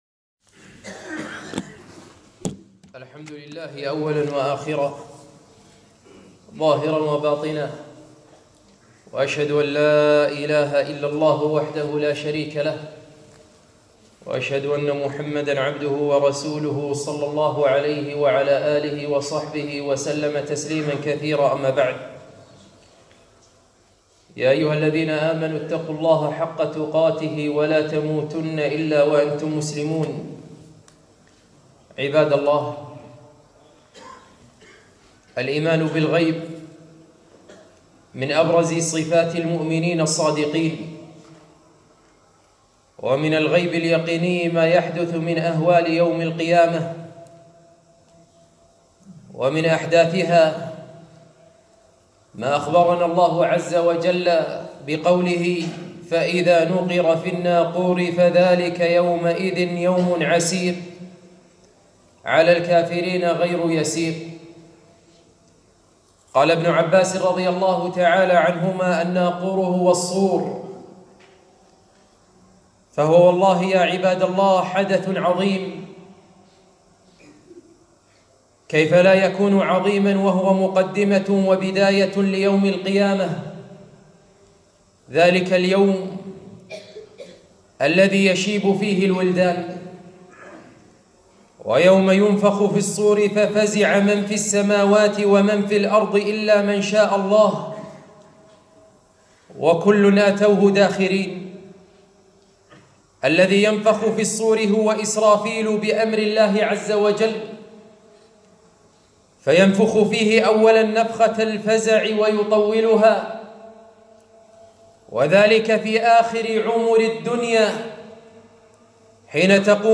خطبة - قد التقم قرنه